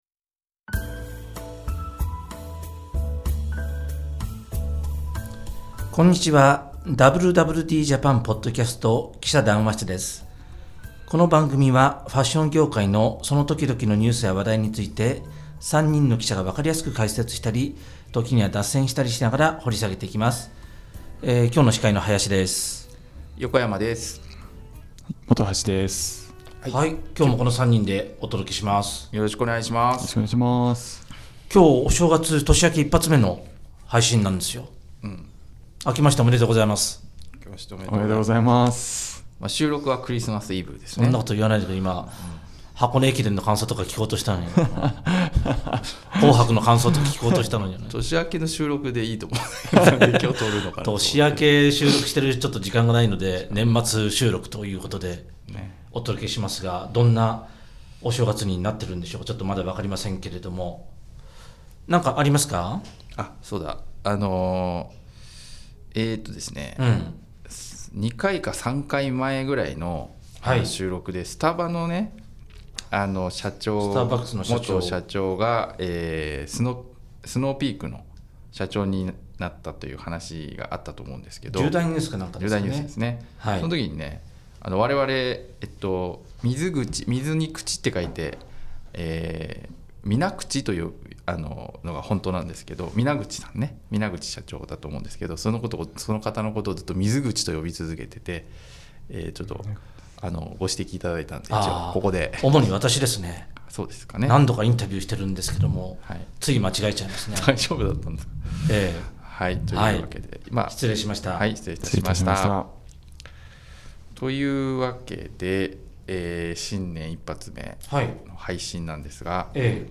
【連載 記者談話室】 「WWDJAPAN」ポッドキャストの「記者談話室」は、ファッション業界のその時々のニュースや話題について、記者たちが分かりやすく解説したり、時には脱線したりしながら、掘り下げていきます。